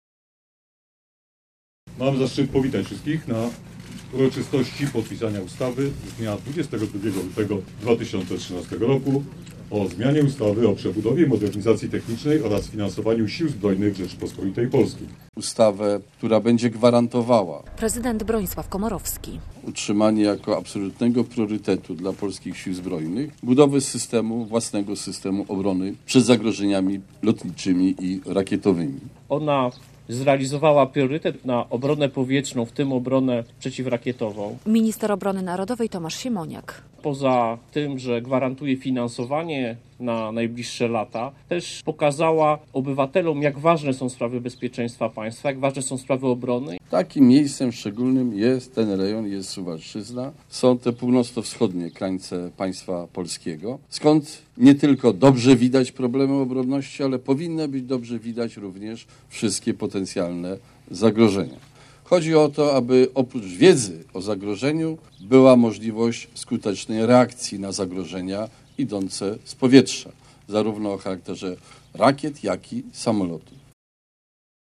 Ustawa o finansowaniu systemu antyrakietowego podpisana - relacja